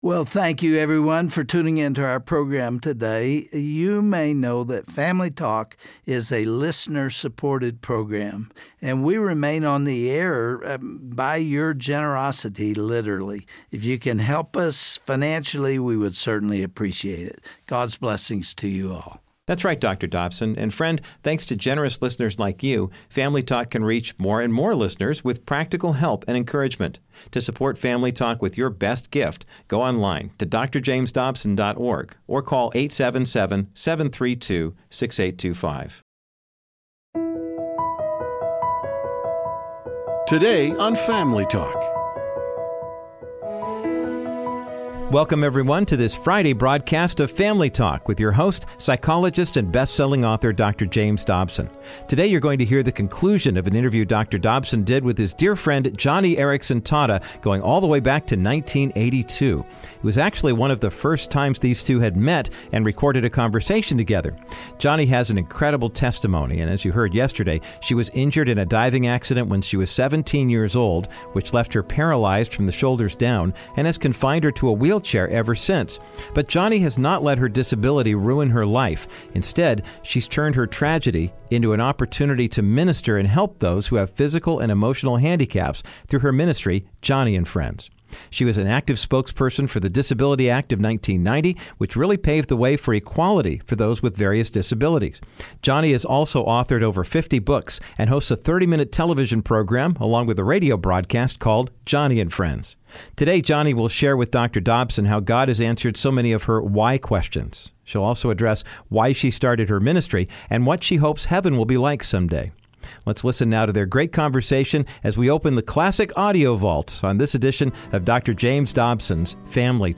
Dr. Dobson concludes his classic and moving conversation with best-selling author and speaker Joni Eareckson Tada. She explains how God comforted her and gave her purpose through her disability, and shares what she thinks Heaven will be like through a personal song.